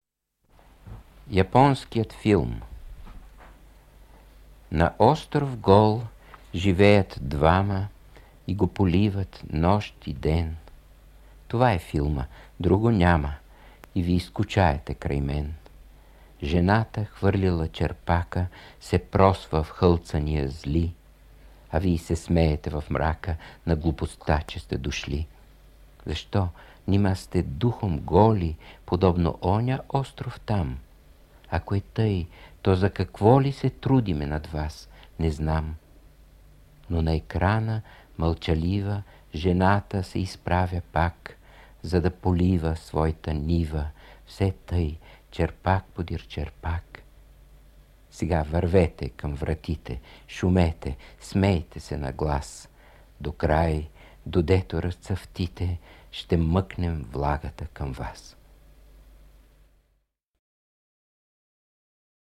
Стихотворението „Японският филм” в негово изпълнение влезе в един от електронните учебници на издателство „Просвета” (чуй го